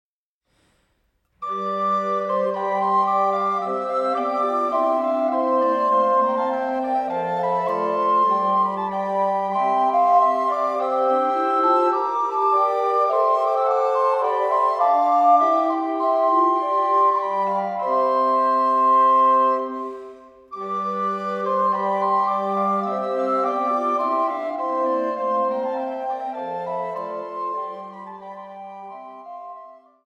Fünfstimmiges Blockflötenconsort